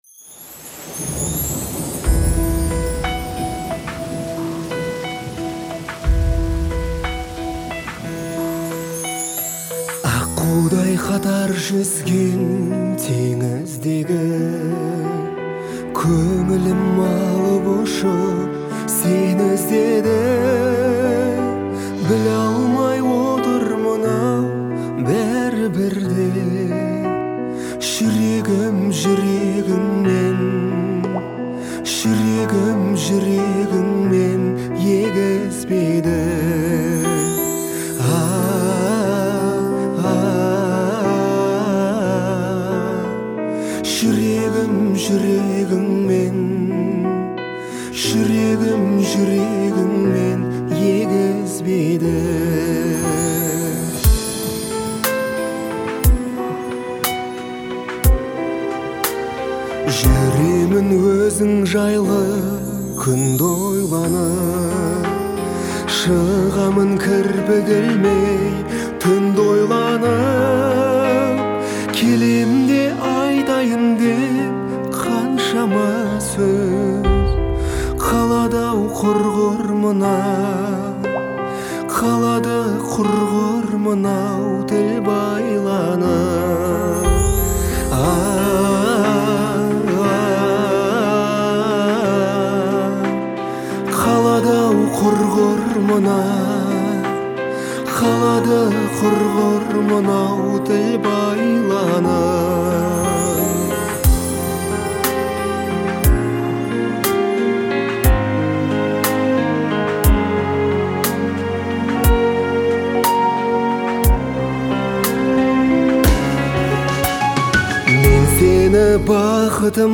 это нежный и мелодичный трек в жанре казахской поп-музыки.